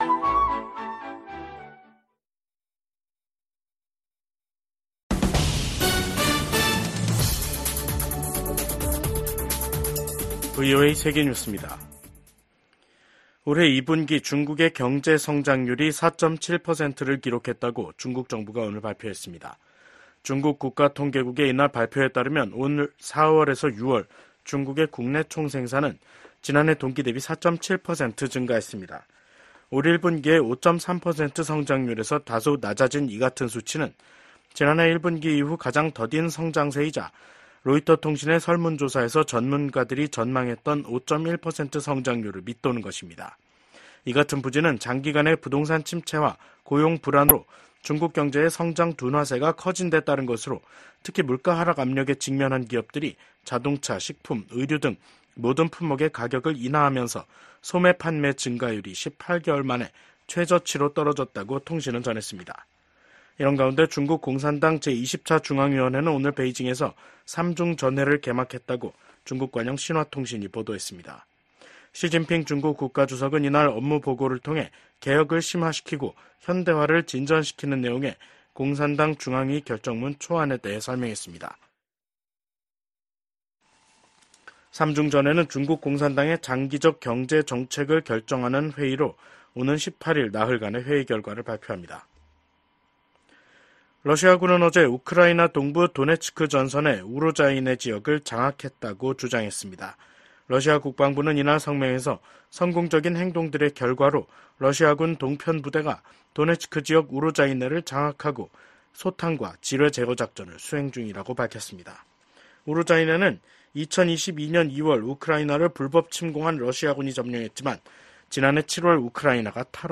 VOA 한국어 간판 뉴스 프로그램 '뉴스 투데이', 2024년 7월 15일 2부 방송입니다. 조 바이든 미국 대통령은 트럼프 전 대통령 피격 사건이 나자 대국민 연설을 통해 폭력은 결코 해답이 될 수 없다고 강조했습니다. 도널드 트럼프 전 대통령에 대한 총격 사건과 관련해 미국 정치권과 각국 정상은 잇달아 성명을 내고 트럼프 전 대통령의 빠른 쾌유를 기원했습니다.